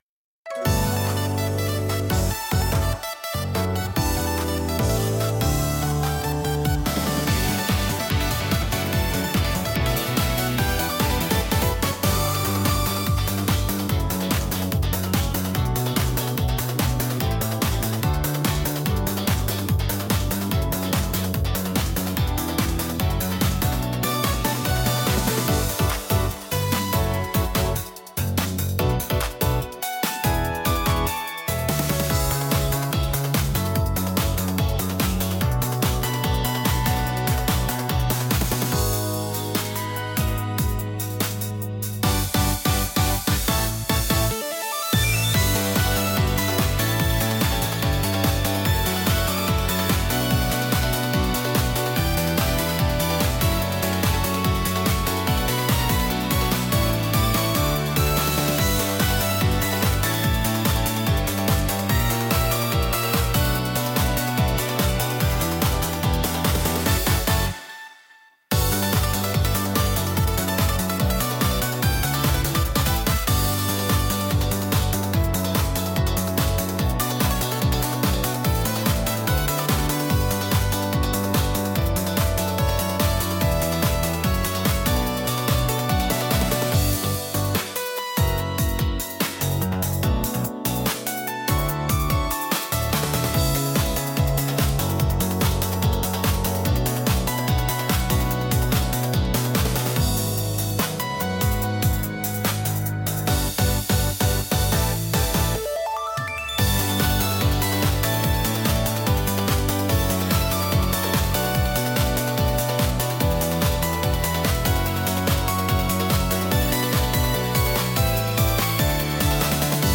聴く人の気分を高め、緊張と興奮を引き立てるダイナミックなジャンルです。